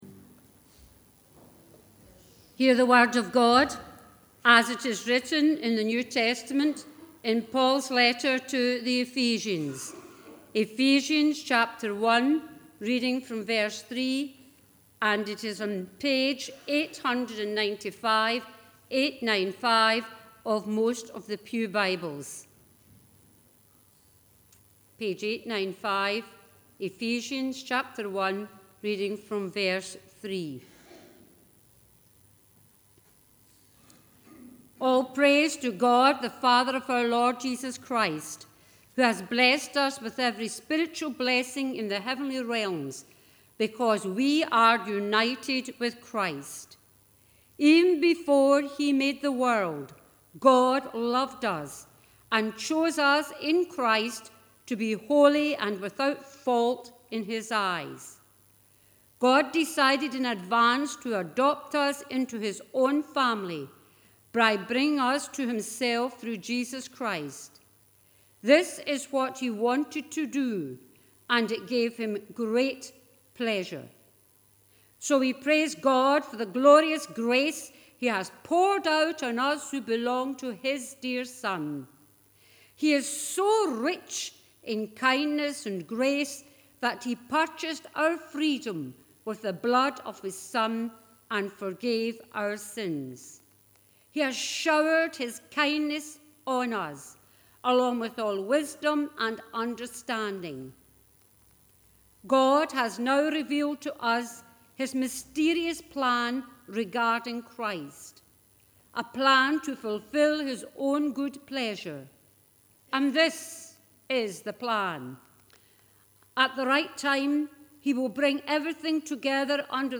The reading prior to the sermon is Ephesians 1: 3-23